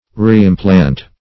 Reimplant \Re`im*plant"\ (-pl?nt"), v. t. To implant again.